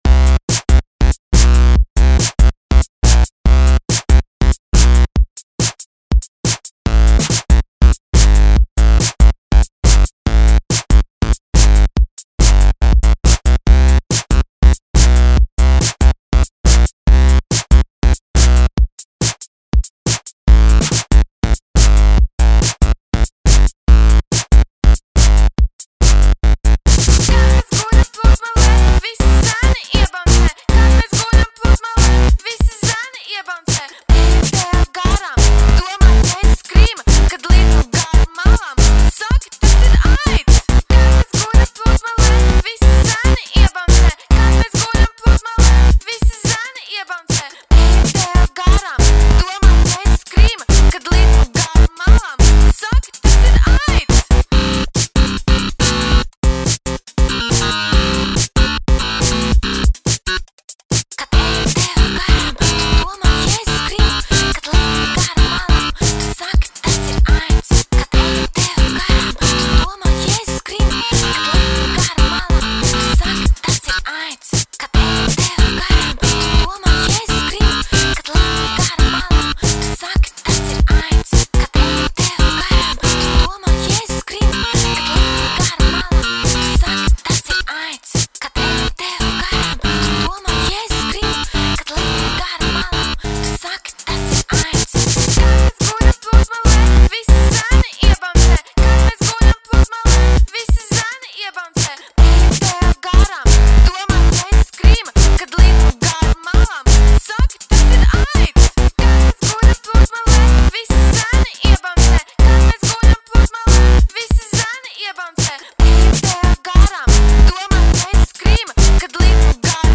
Here is a song from her pop group